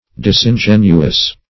Disingenuous \Dis`in*gen"u*ous\, a.